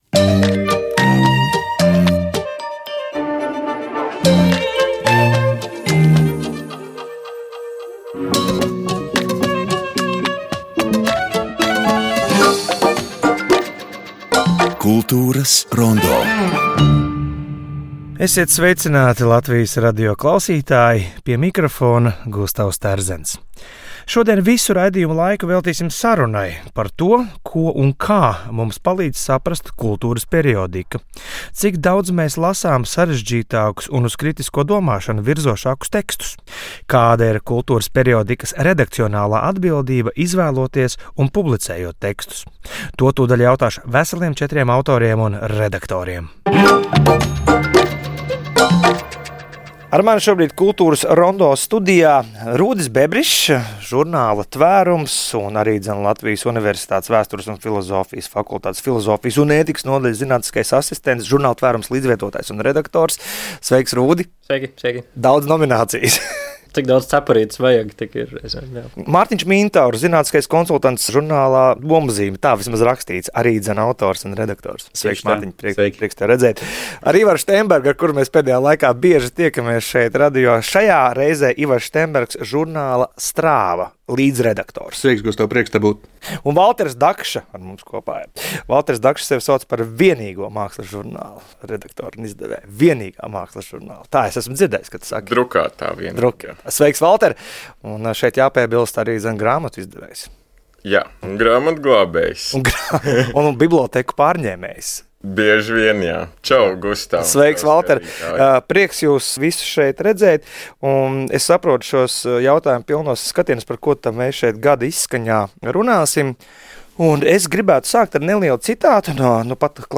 Mēs ne tikai palīdzam orientēties kultūras notikumos, bet tiešraides sarunās apspriežam kultūras notikumu un kultūras personību rosinātas idejas.
Tās ir diskusijas, kurās satiekas kultūras notikumu radītāji, kultūras dzīves organizatori un kultūras patērētāji. "Kultūras rondo" tiešajās pārraidēs ir klāt svarīgos kultūras notikumos visā Latvijā, kas ļauj nepastarpināti iepazīt kultūras personības un kultūras telpu arī ārpus Rīgas un saglabāt arhīvā būtiskas liecības par notikumiem.